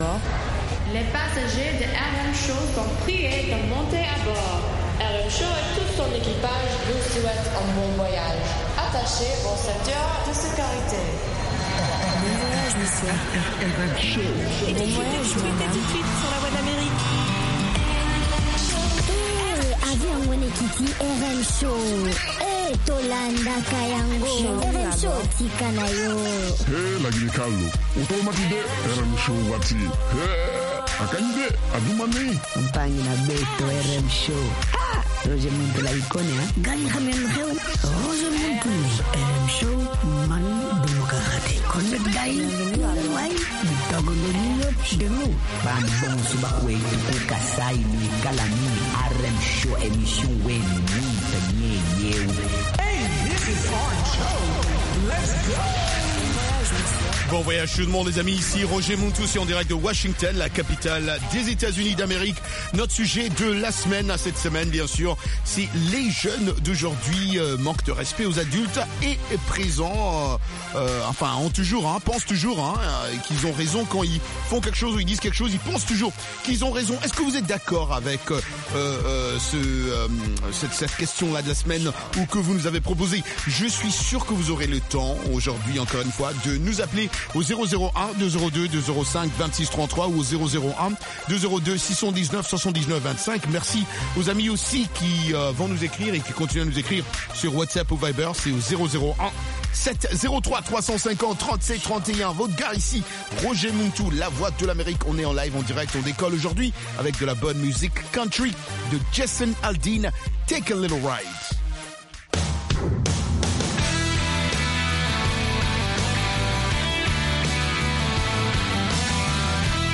Balade musicale dans le monde entier, rions un peu avec de la comédie, interviews des divers artistes